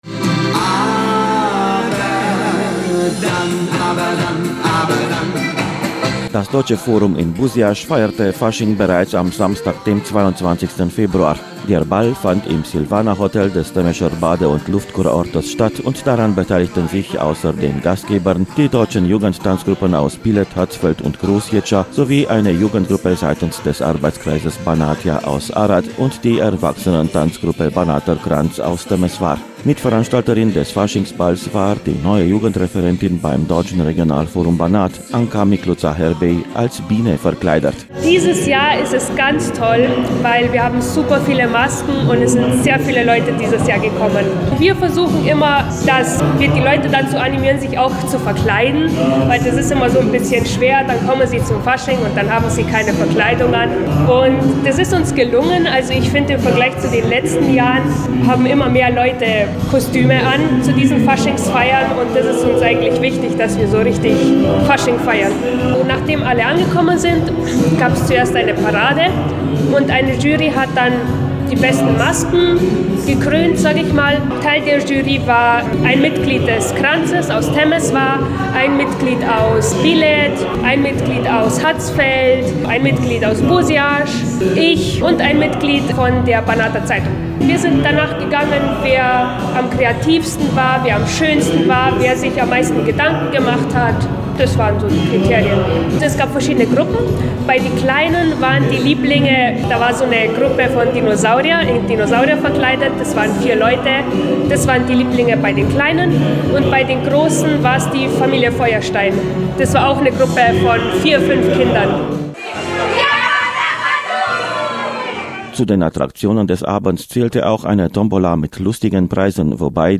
Mehrere Faschingsunterhaltungen veranstalteten die Organisationen der deutschen Gemeinschaft in Westrumänien in der diesjährigen lustigen 5. Jahreszeit. Die deutschen Tanzgruppen der Jugendlichen und Erwachsenen aus dem Verwaltungskreis Temesch feierten in Busiasch am 22. Februar, die Gruppen aus dem Norden des Banats kamen in Neuarad am 28. Februar zusammen und die des Banater Berglands am 1. März in Reschitza.